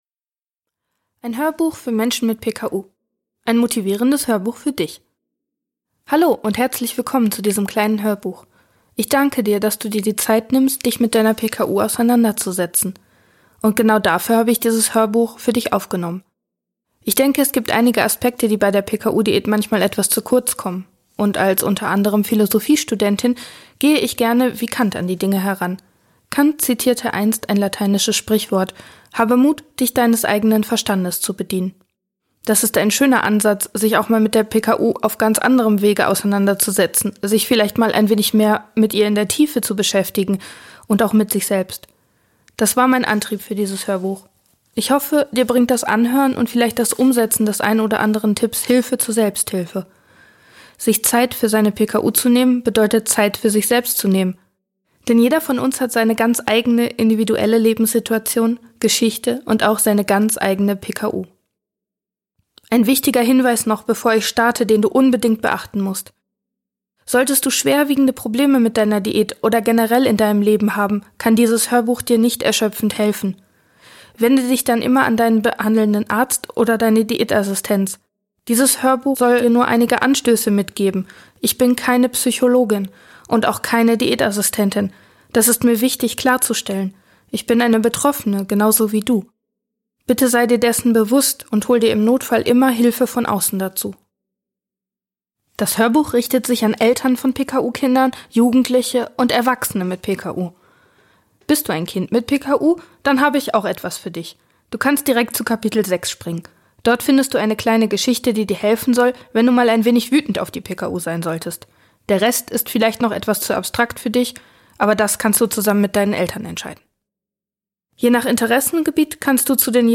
Beschreibung vor 3 Monaten Zu Überrbrückung für die Feiertage, gibt es für euch mein komplettes PKU-Hörbuch als Throwback!